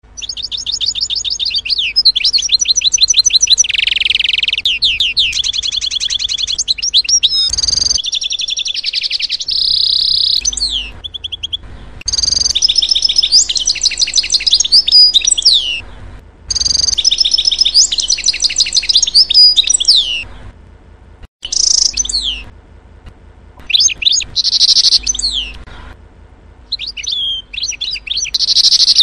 0:00 Group: Tiere ( 756 243 ) Rate this post Download Here!